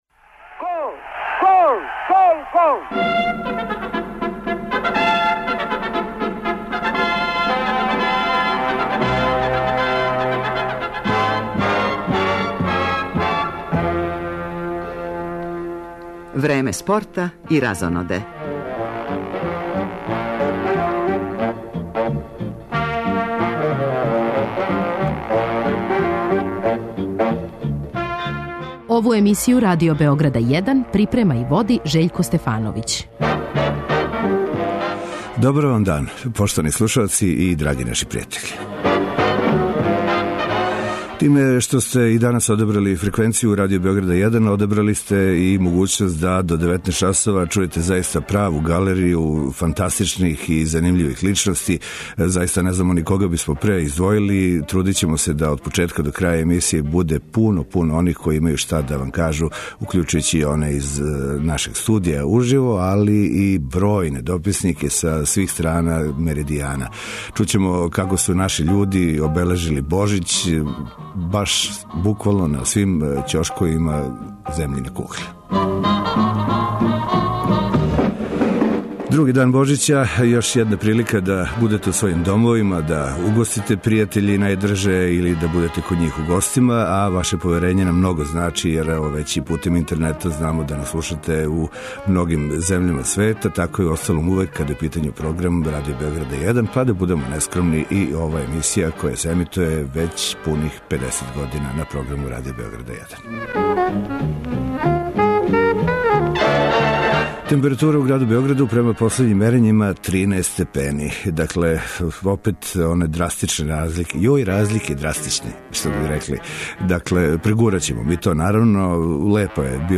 И ове суботе бележимо актуелне догађаје из земље и иностранства, из домена спорта, театра, дискографске индустрије, гастрономије, здравог живота. Гост у студију Радио Београда 1 је Зорана Аруновић, која је прошле године била неприкосновена од наших представника у свету стрељаштва и, као таква, у највећем броју анкета проглашена је за најуспешнију спортисткињу 2010. године.
Ексклузивни гост емисије је Роберт Просинечки, нови тренер фудбалског клуба Црвена звезда.